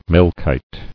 [Mel·chite]